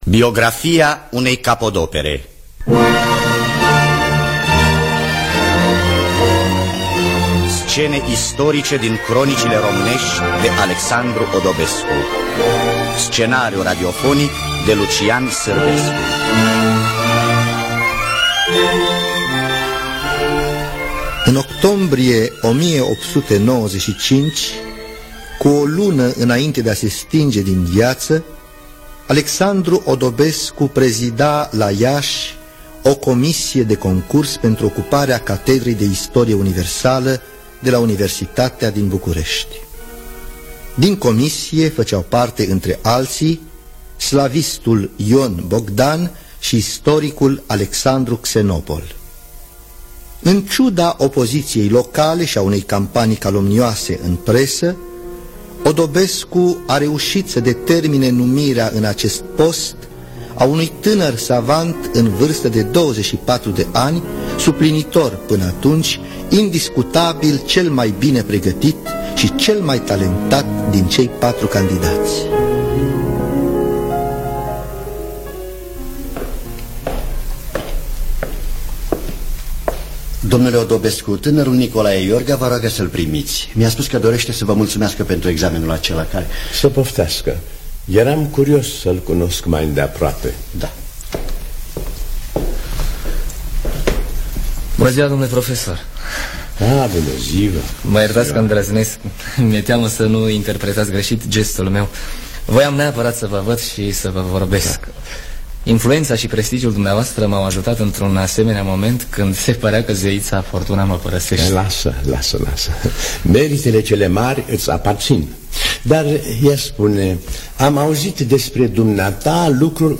Biografii, Memorii: Alexandru Odobescu – Scene Istorice Din Cronicile Romanesti (1975) – Teatru Radiofonic Online